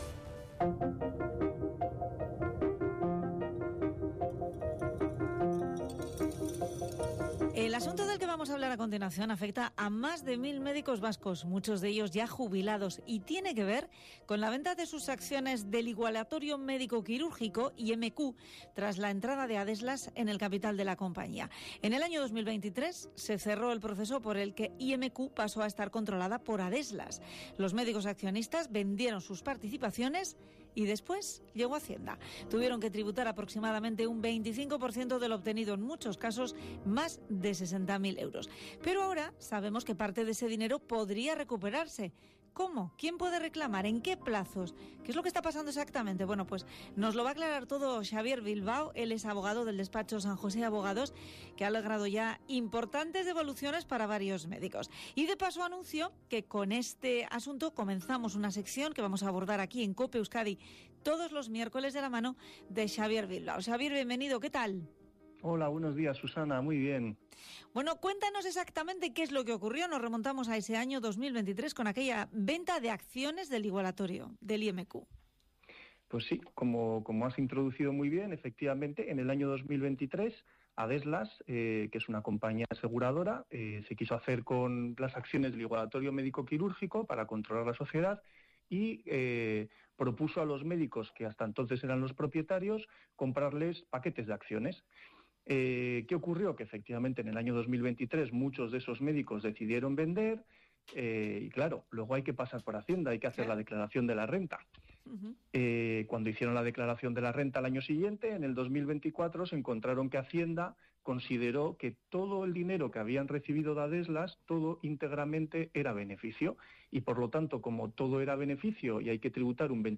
ENTREVISTA EN COPE SOBRE LAS RECLAMACIONES DE LOS MEDICOS DE IMQ A HACIENDA - Despacho Abogados San Jose
ENTREVISTA-18-FEBRERO.mp3